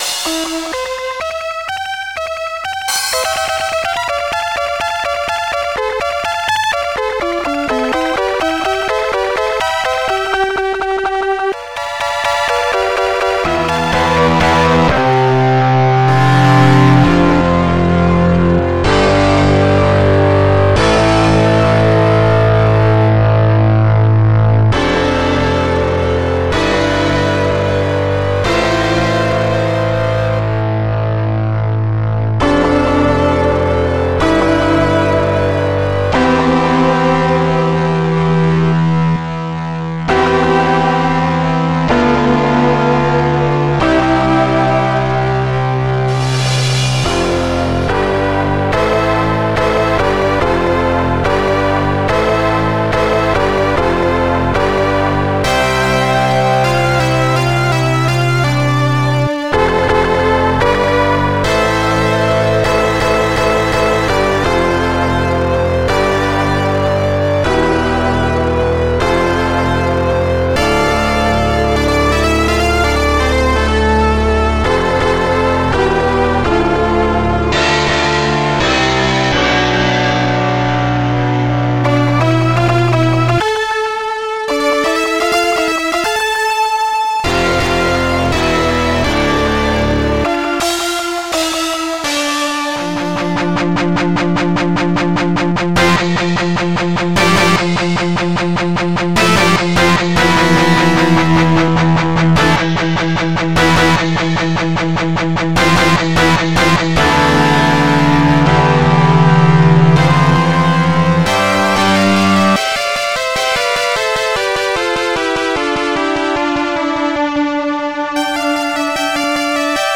Protracker Module
st-99:crash st-99:soloecho st-99:heavyguitar97 st-99:solopriest st-99:hardscratch